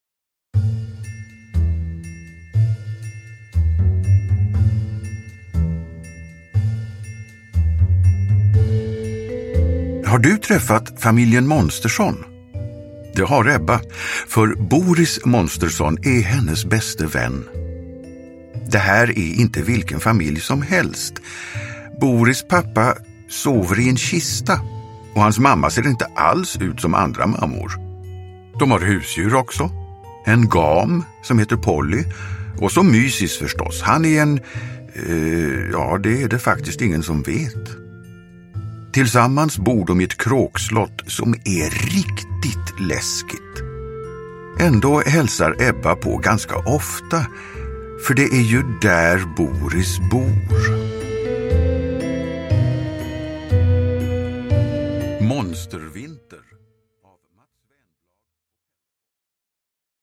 Monstervinter – Ljudbok – Laddas ner